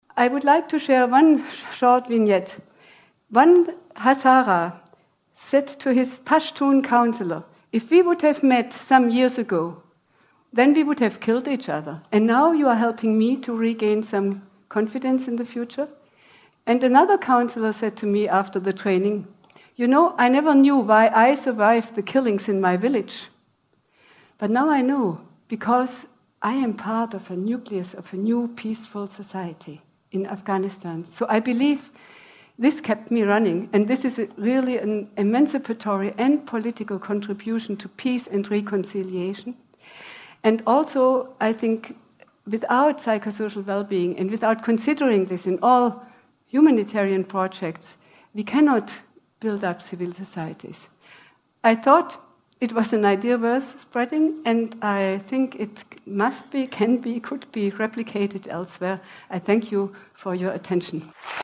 TED演讲:给阿富汗人带来心灵宁静(7) 听力文件下载—在线英语听力室